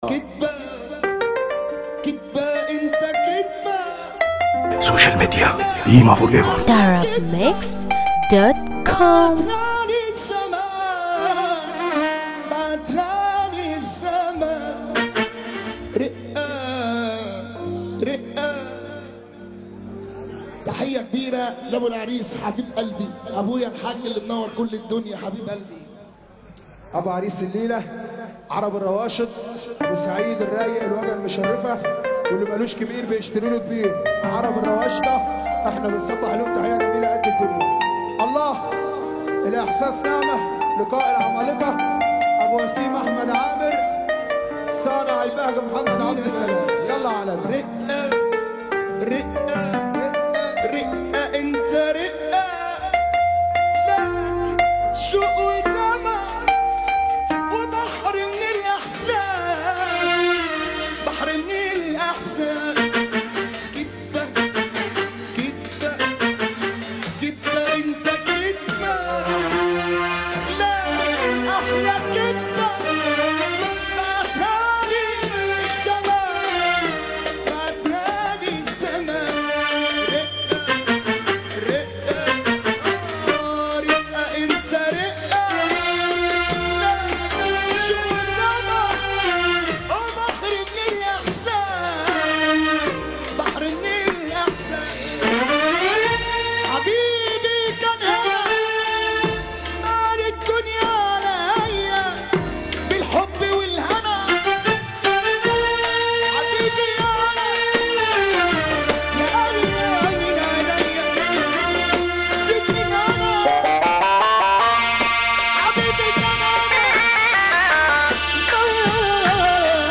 موال
حزينة جدا